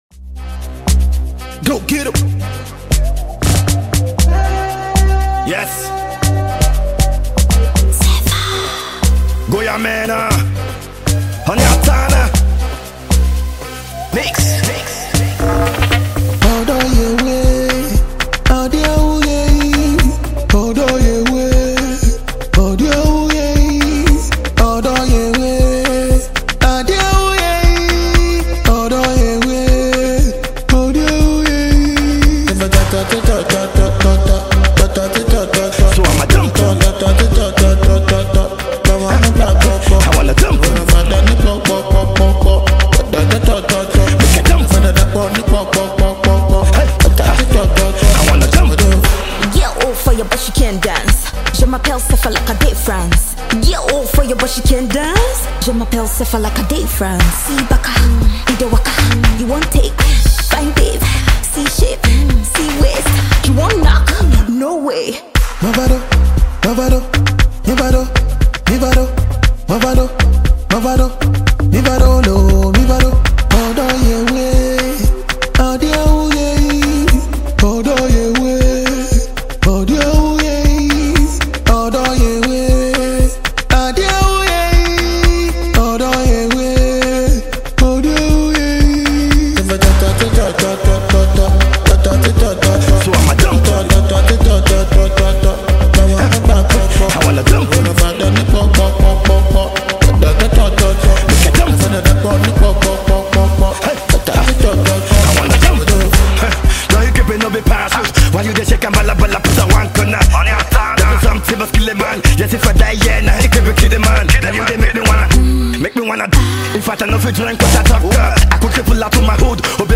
Ghanaian multi-talented rapper and songwriter